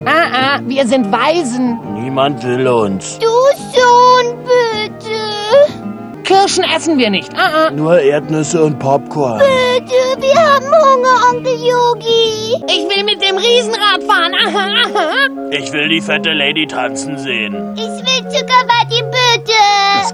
Na da hab ich mir wieder was aufgehalst Über ein Dutzend "Kinderrollen" mit Sprechern, die (wie im Original) wohl keine Kinder mehr sind.